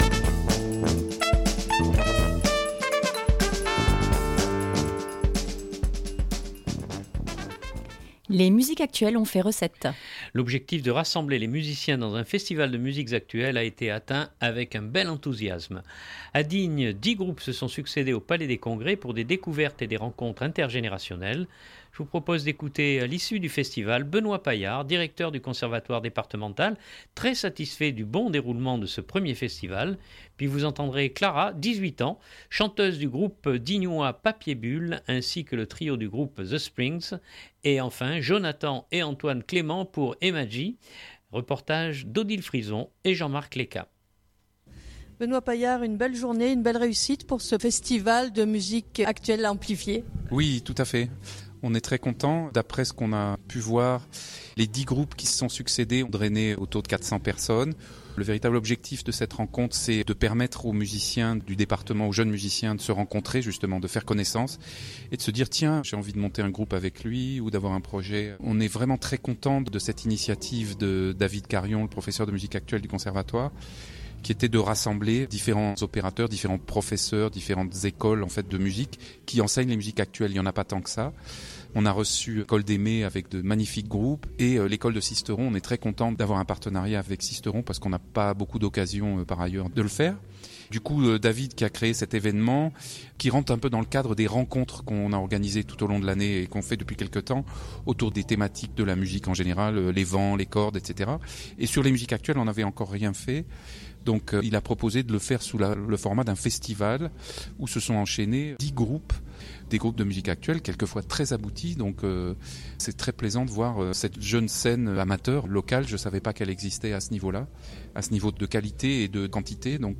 L’objectif de rassembler les musiciens dans un Festival de musiques actuelles a été atteint avec un bel enthousiasme. A Digne, 10 groupes se sont succédé au Palais des congrès pour des découvertes et des rencontres intergénérationnelles.